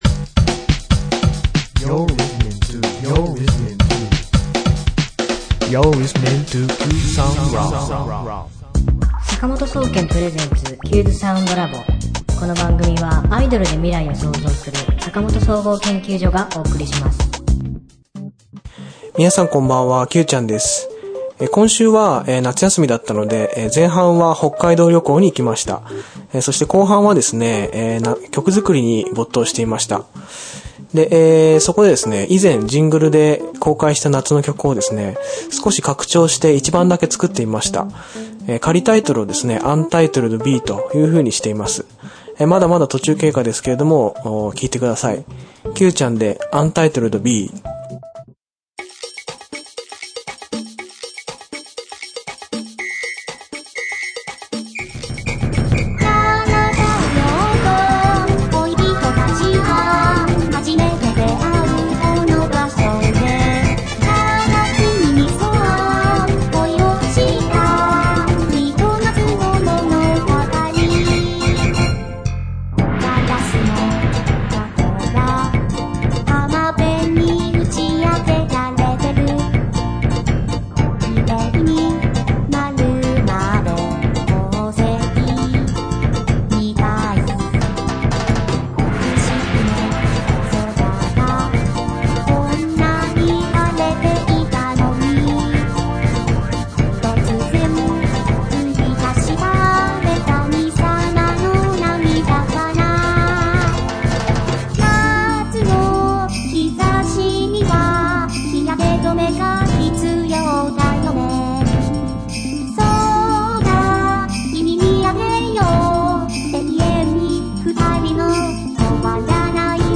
作詞・作曲・編曲：坂本総合研究所
コーラス・歌：初音ミク